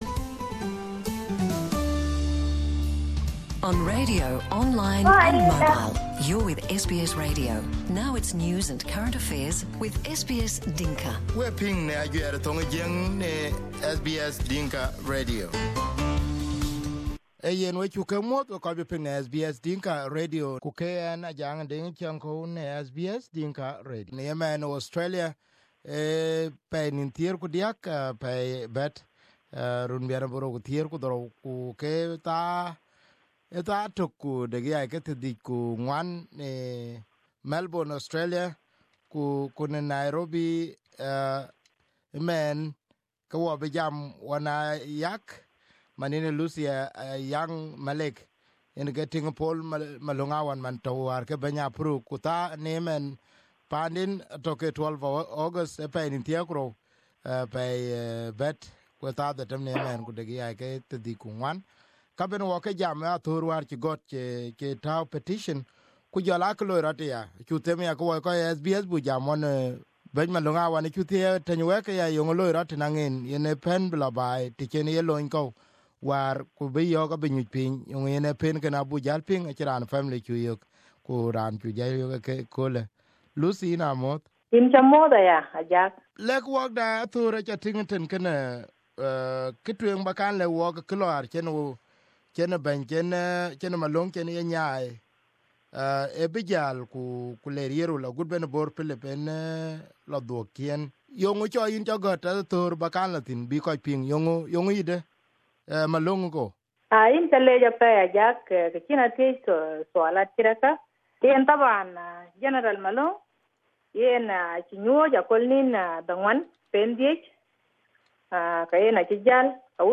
We manage to interview